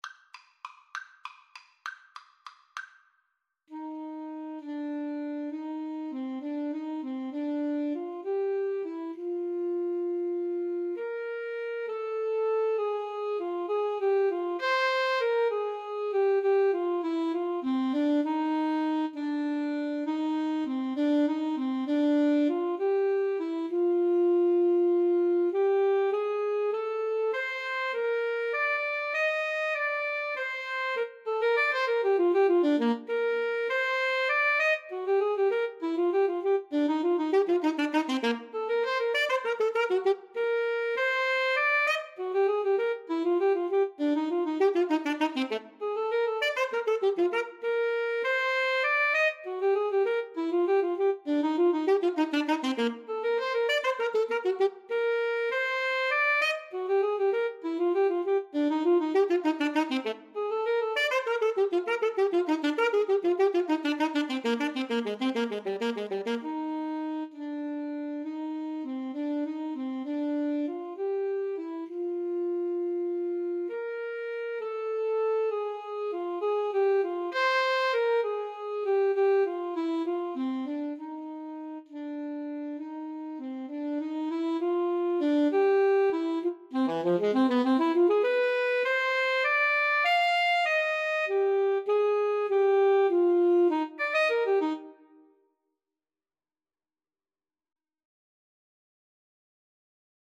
Free Sheet music for Alto Saxophone Duet
Eb major (Sounding Pitch) Bb major (French Horn in F) (View more Eb major Music for Alto Saxophone Duet )
One in a bar c. .=c.66
3/4 (View more 3/4 Music)
Classical (View more Classical Alto Saxophone Duet Music)